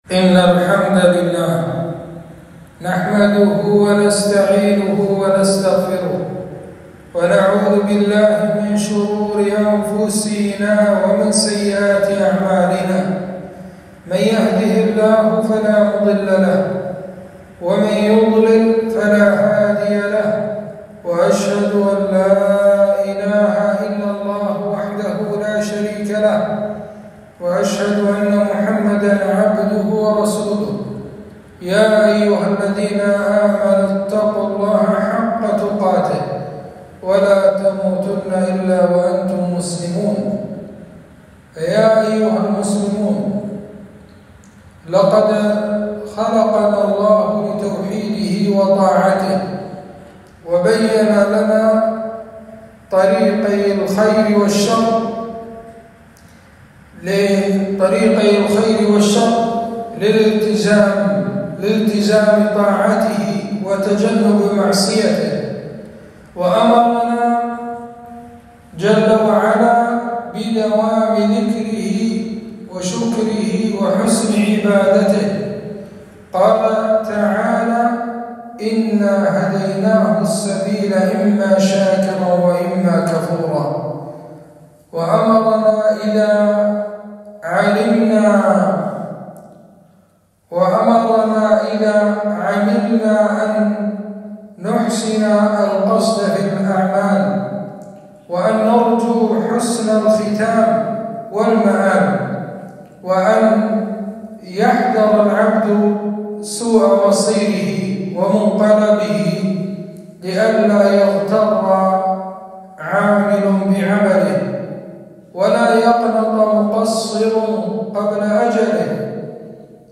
خطبة - احسنوا الختام فإن الأعمال بالخواتيم